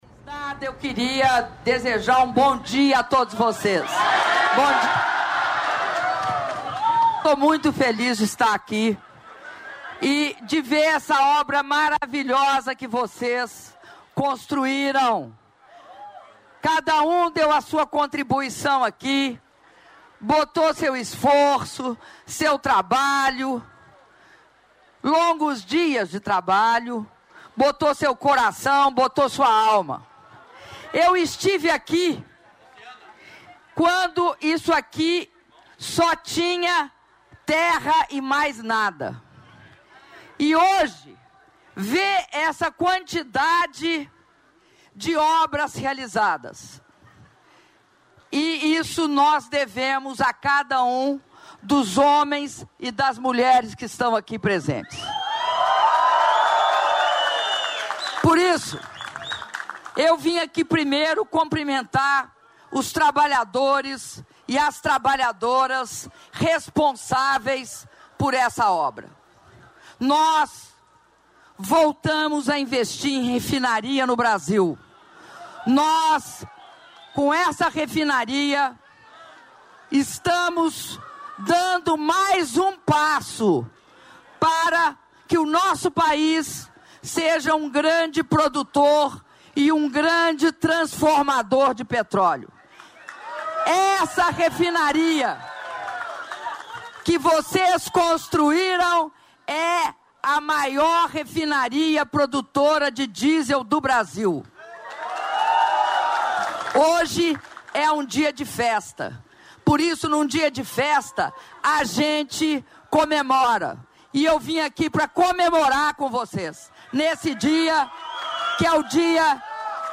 Áudio das palavras da Presidenta da República, Dilma Rousseff, durante visita às obras da Refinaria Abreu e Lima - Ipojuca/PE (05min09s)